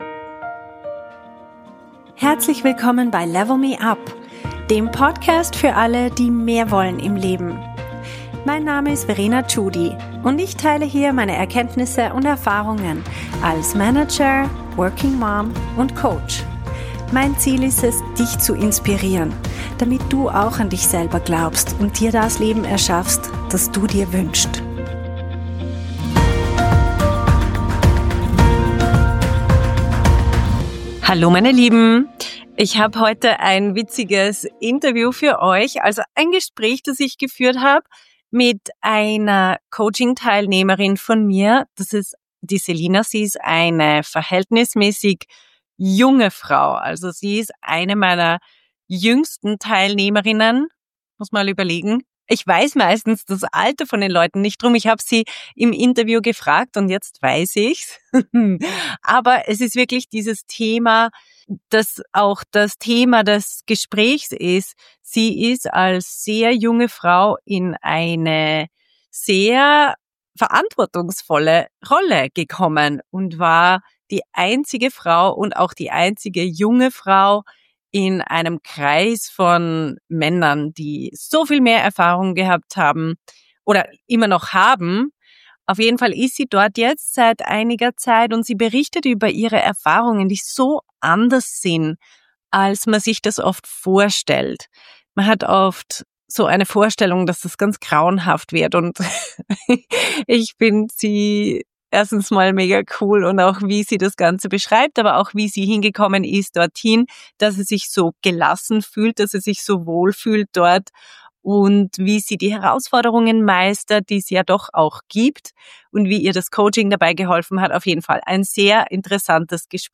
Erfolg als junge Frau in der IT - Interview